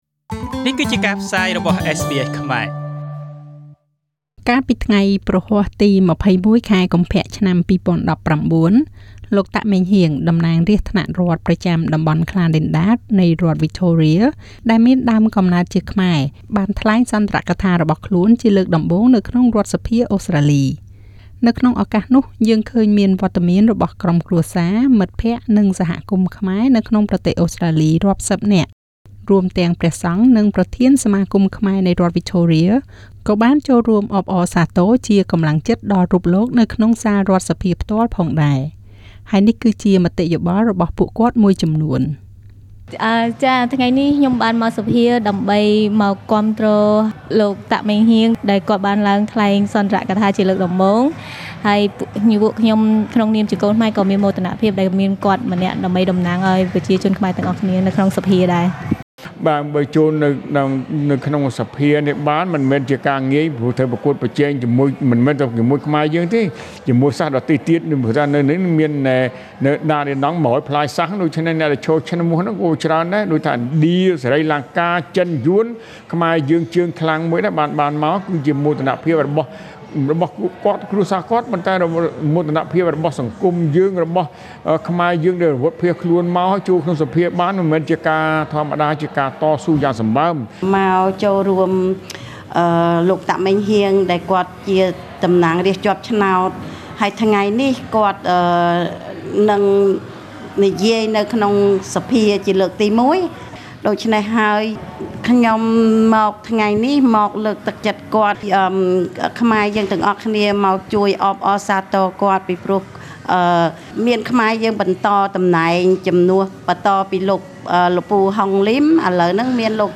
Victorian MP Meng Heang Tak makes his inaugural speech in Khmer
Newly elected MP Meng Heang Tak made his first speech in Victorian parliament on 21st of February 2019, with the supports from family, friends and dozens of Khmer community including Buddhist monks. He has also asked the speaker to have few words in Khmer.
Victorian MP Meng Heag Tak speech at the parliament.